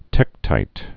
(tĕktīt)